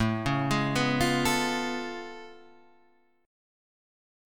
A Augmented Major 9th